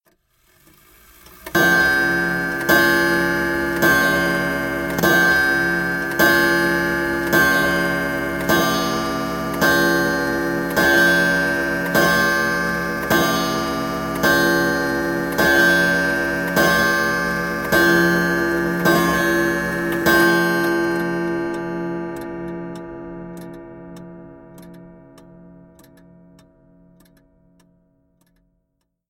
17 Chime
Tags: clock